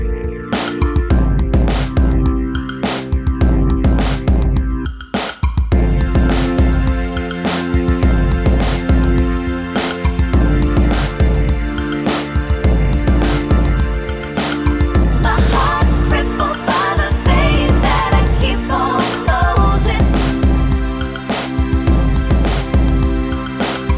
Two Semitones Down Pop (2000s) 4:22 Buy £1.50